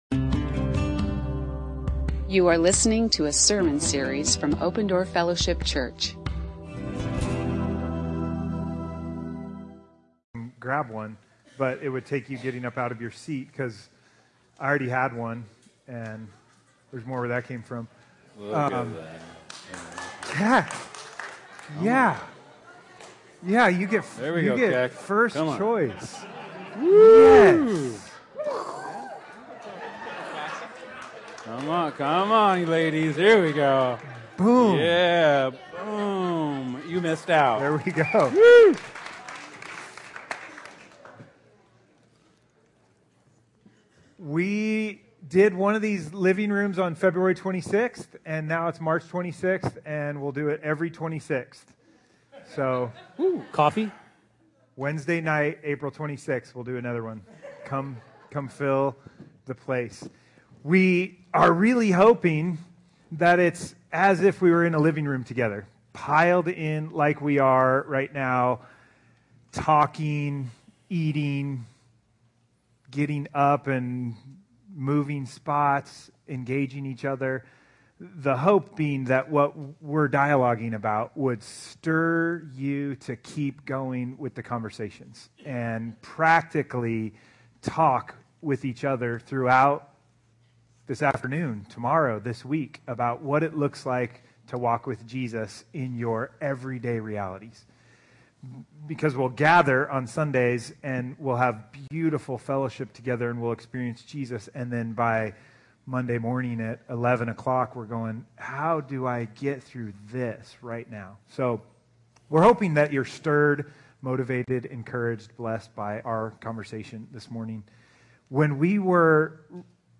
AZ You are listening to an audio recording of Open Door Fellowship Church in Phoenix, Arizona.